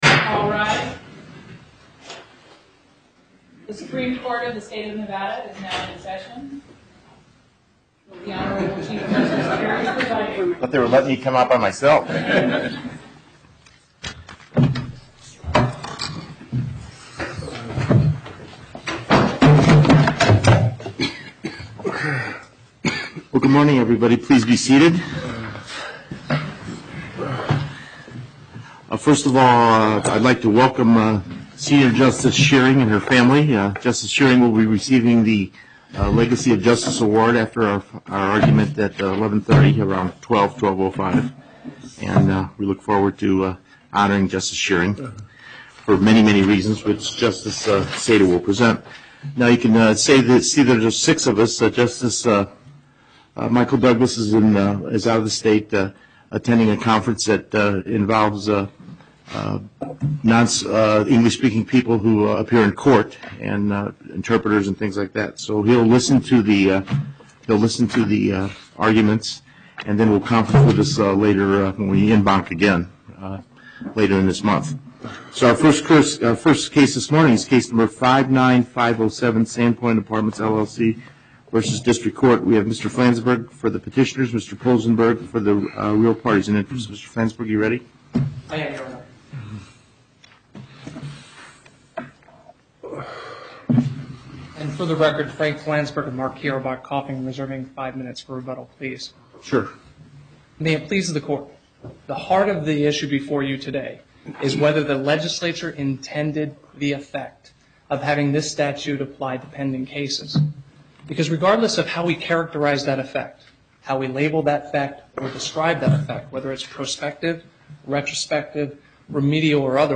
Before the En Banc Court, Chief Justice Cherry presiding